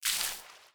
SFX_Water_Attack_Slimes.wav